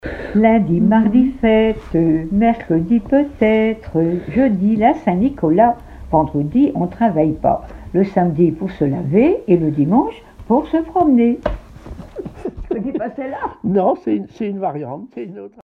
Fonction d'après l'analyste enfantine : comptine ;
Catégorie Pièce musicale inédite